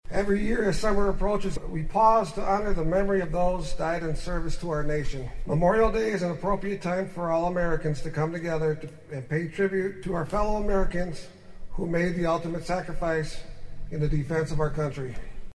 American Legion Post 17 hosted its annual Memorial Day ceremony in Sunset Cemetery on Monday, honoring those who had died in the fight for freedom.